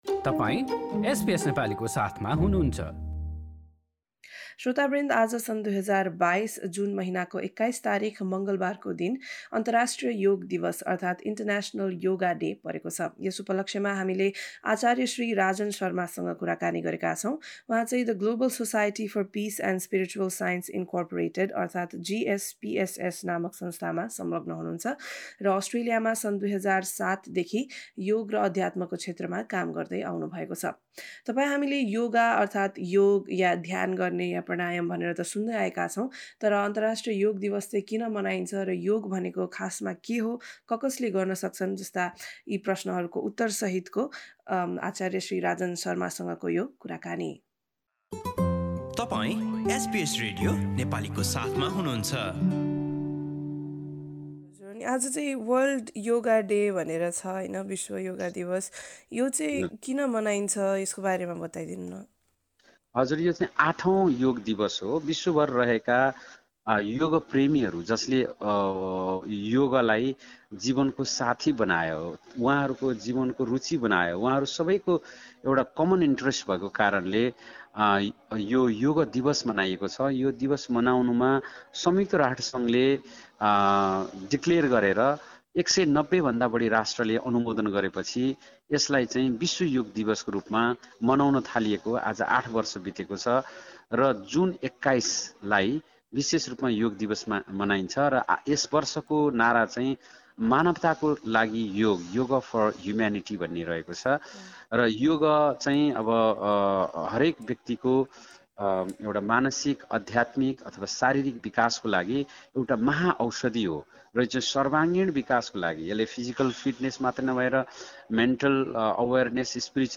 कुराकानी सुन्नुहोस्: null हाम्रा थप अडियो प्रस्तुतिहरू पोडकास्टका रूपमा उपलब्ध छन्।